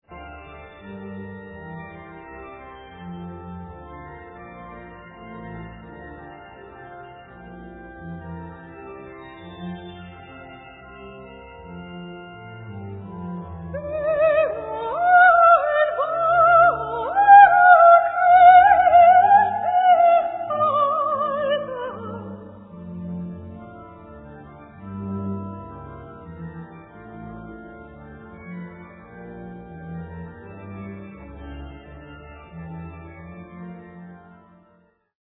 An historic recording
tenor
Aria (S) - 11:12